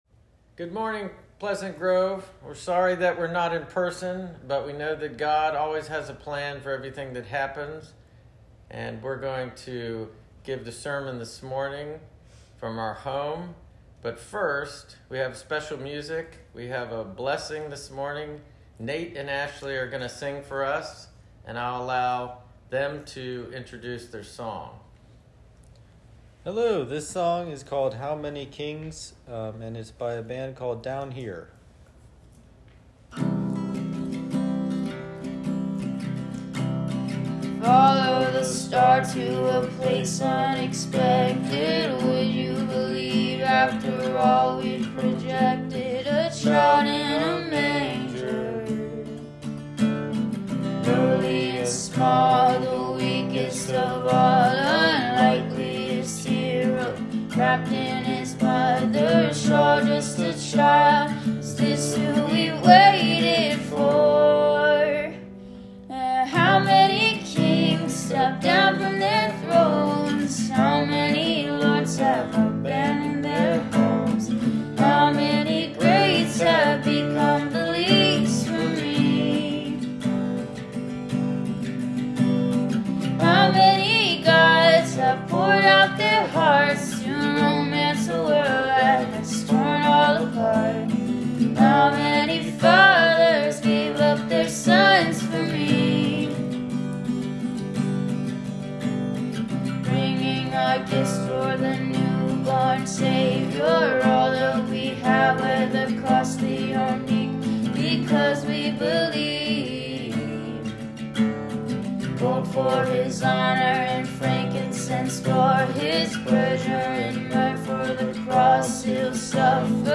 Joy-Sermon-with-Special-Music.m4a